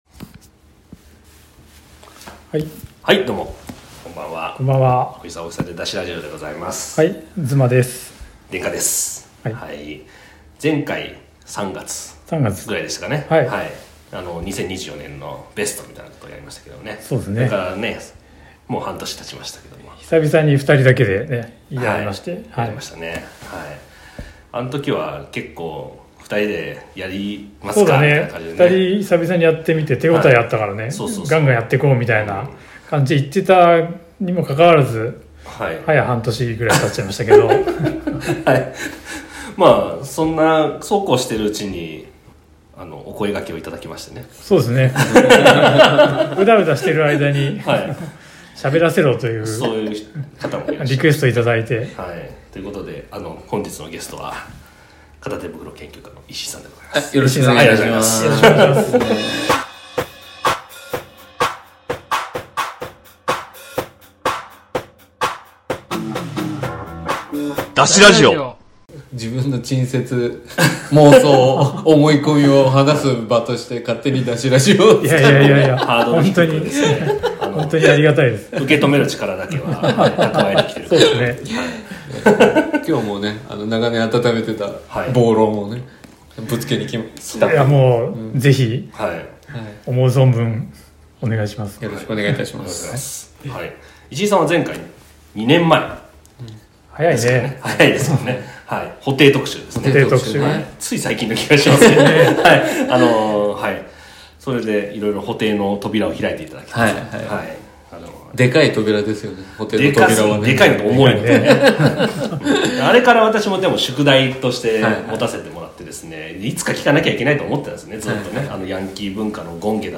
同じく路上にあるものを集めることを趣味にしたパーソナリティが、路上のものを集める理由、撮る理由について聞きました。